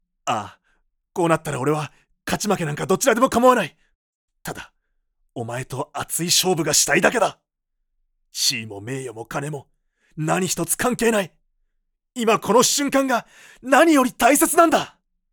I can adapt my voice to your needs, from a calm tone to a bouncy, energetic voice.
Can speak Kansai dialect.
Passionate man